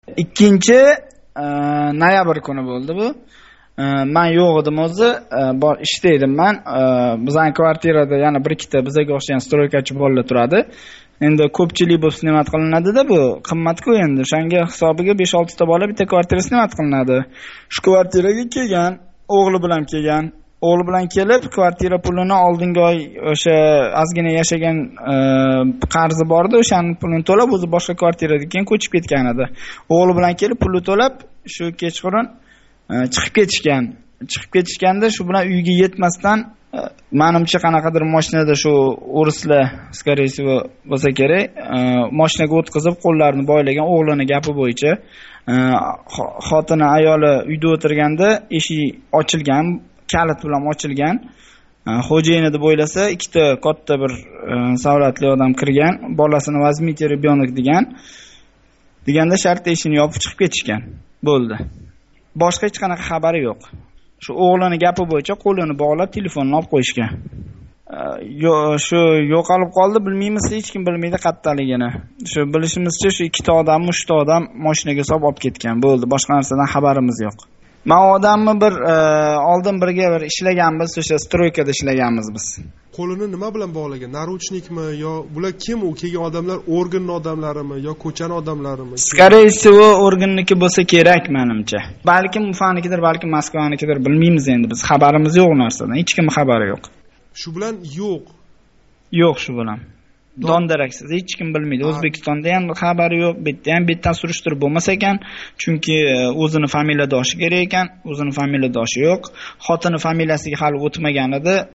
Ўзбек меҳнат муҳожири билан суҳбат